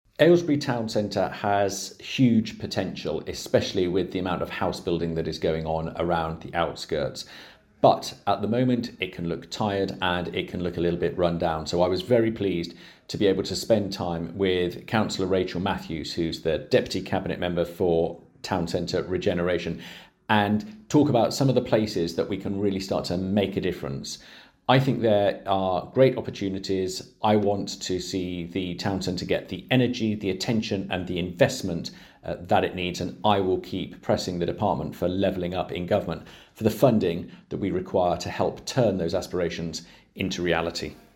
Speaking to Bucks Radio - Mr Butler said the town centre has huge potential: